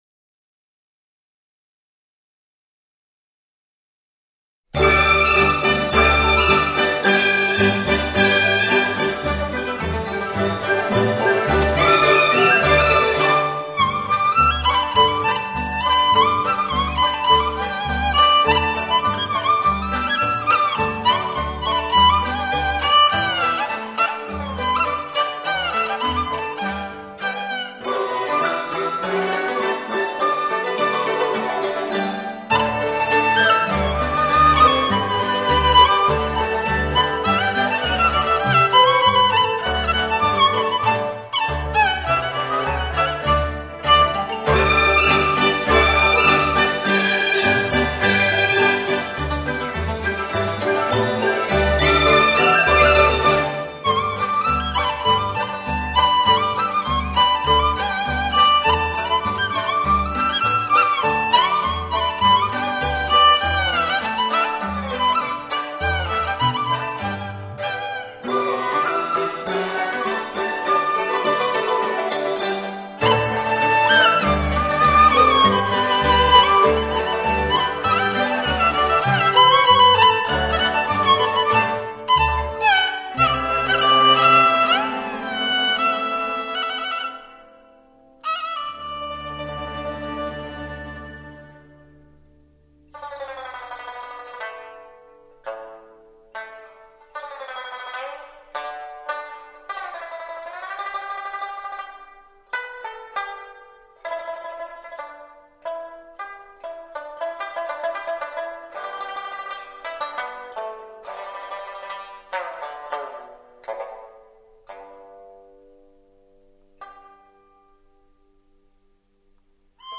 其演奏风格洒脱而细腻。
很喜欢..都收下了..支持民乐!!